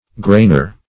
grainer - definition of grainer - synonyms, pronunciation, spelling from Free Dictionary
Grainer \Grain"er\ (gr[=a]n"[~e]r), n.